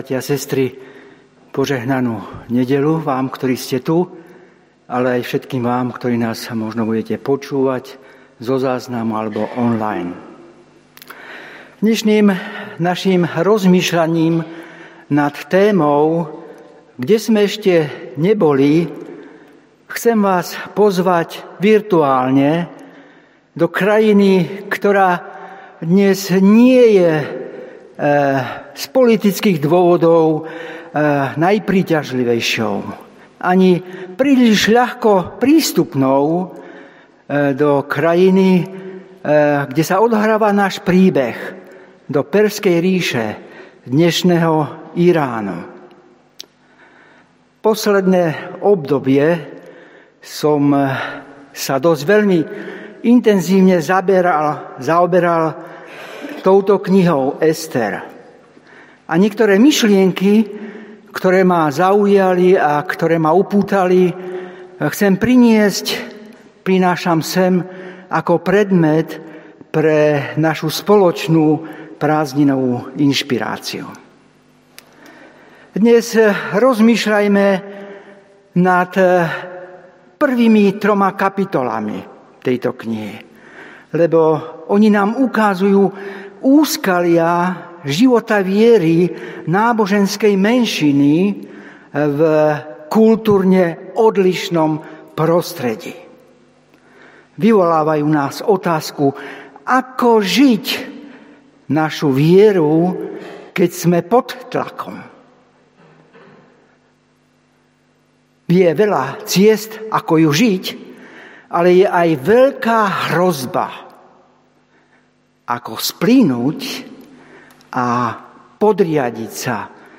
Stránka zboru Cirkvi bratskej v Bratislave - Cukrová 4
3:1-6 Podrobnosti Kázeň Prehliadač nepodporuje prehrávač.